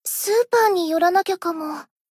贡献 ） 分类:蔚蓝档案语音 协议:Copyright 您不可以覆盖此文件。